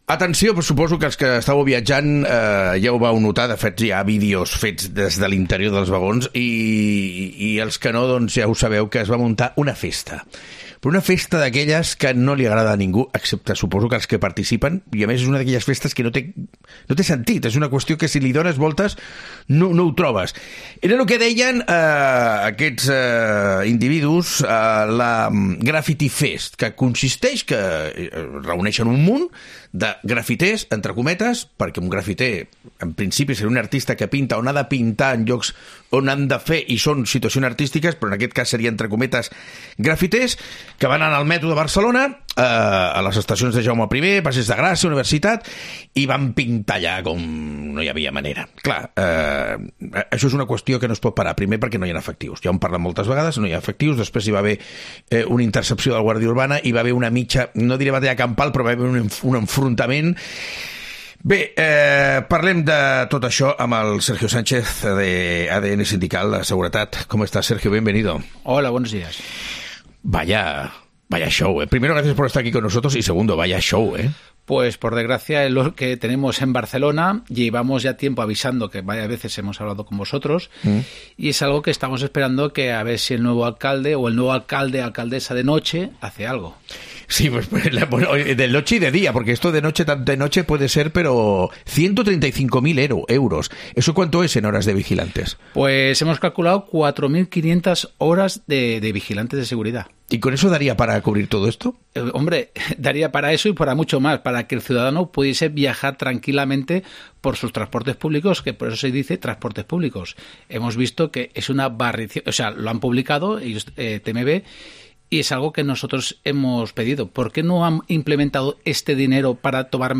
Entrevistamos a